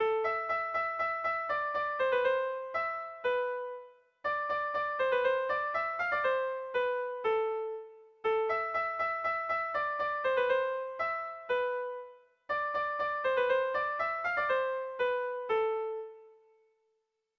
Erlijiozkoa
Lauko txikia (hg) / Bi puntuko txikia (ip)
AB